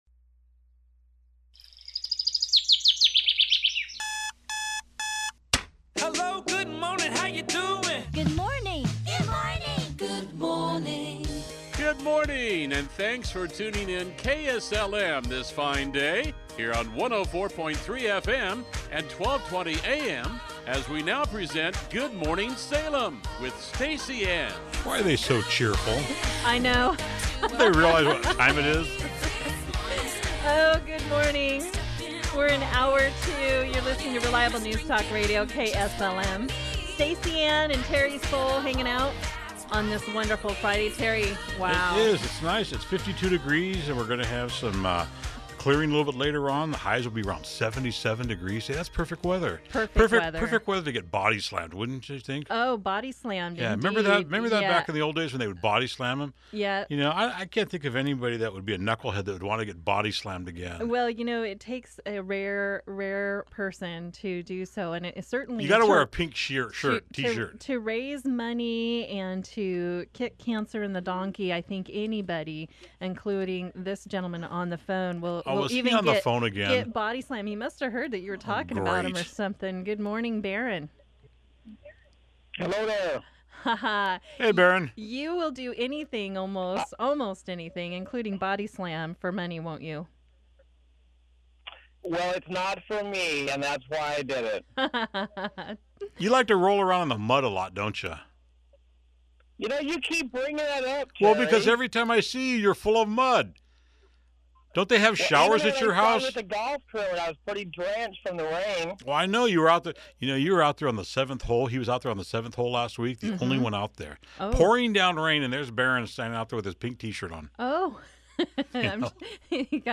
special guest co-host